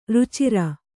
♪ rucira